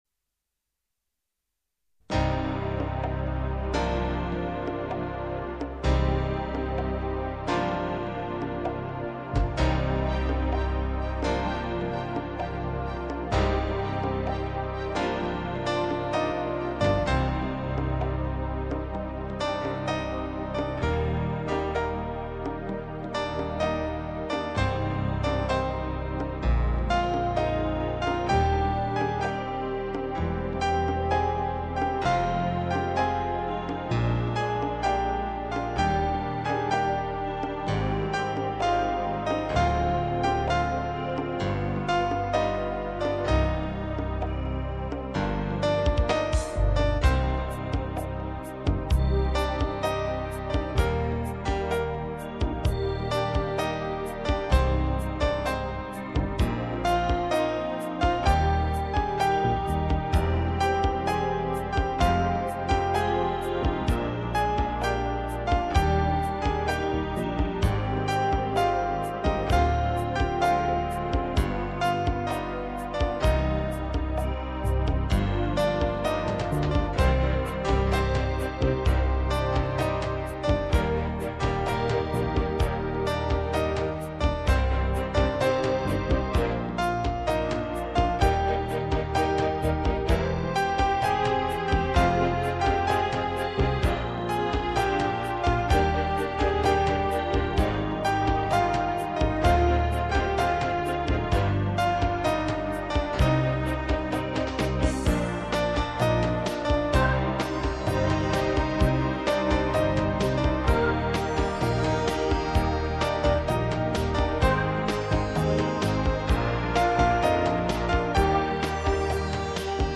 GenereWorld Music / New Age